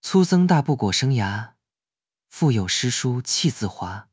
Text-to-Speech
Spark TTS finetuned in genshin charactors voices.